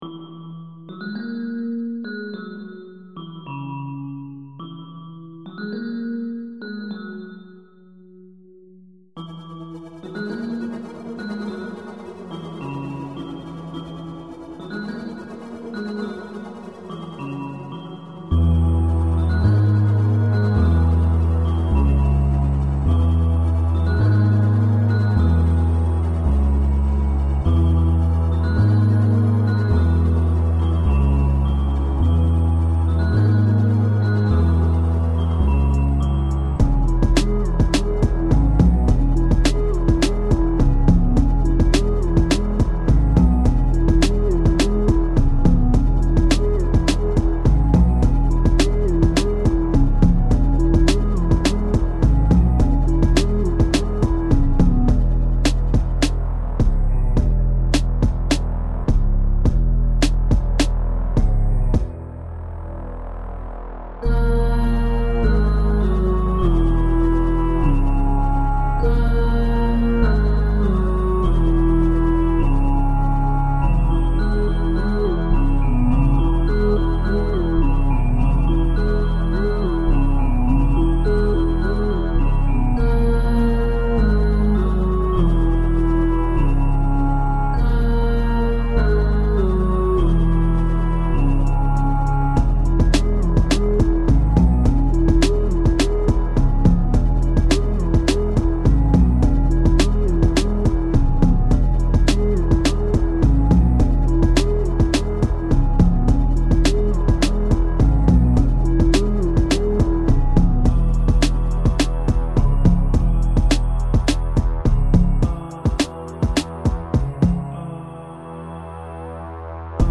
Recollections, remastered and reworked.
Overall? Dreamy.